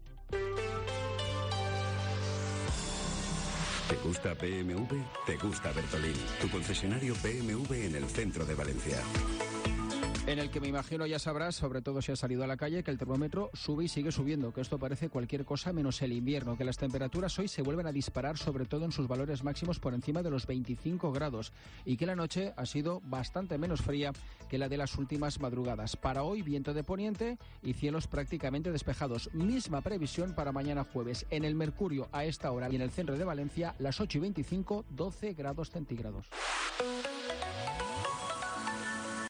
PRONÓSTICO DEL TIEMPO